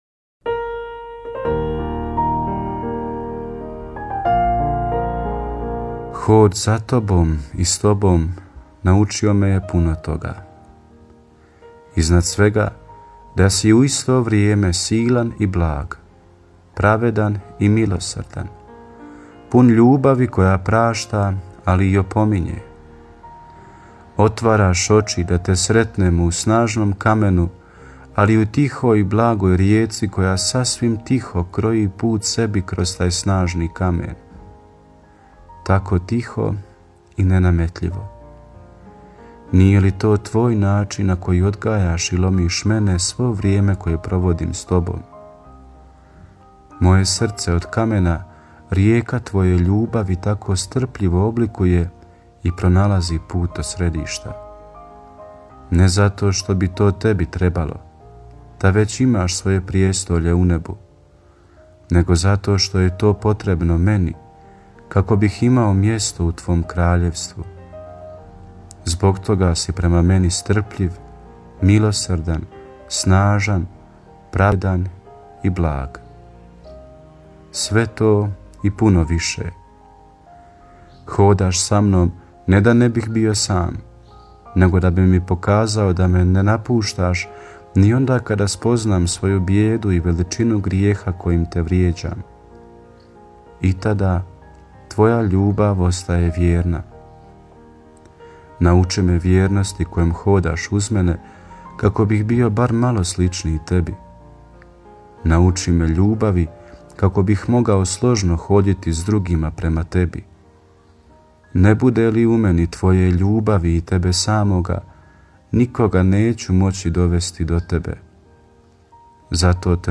Kratku emisiju ‘Duhovni poticaj – Živo vrelo’ slušatelji Radiopostaje Mir Međugorje mogu slušati od ponedjeljka do subote u 3 sata, te u 7:10.